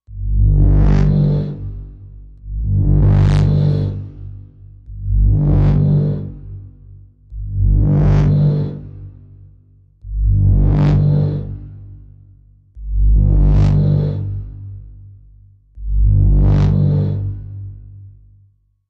Low Pulse, Machine, Low Pulse, Cycle, Warning